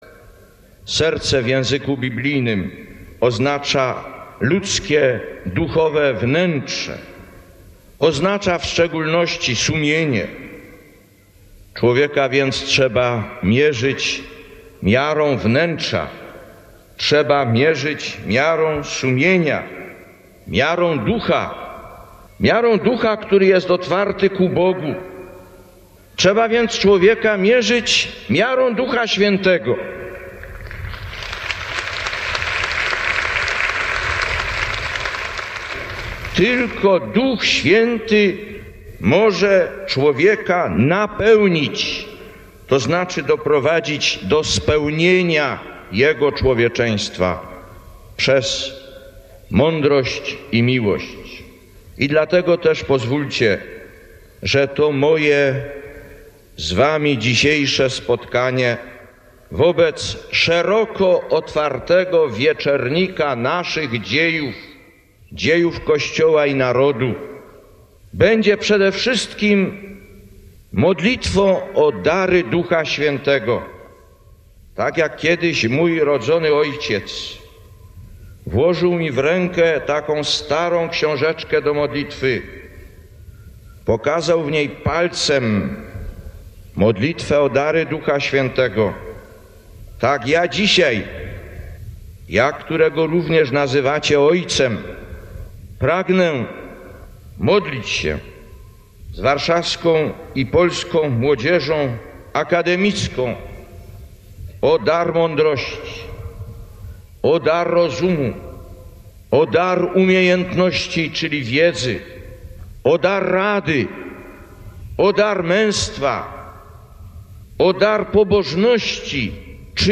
Mówi do nas Św. Jan Paweł II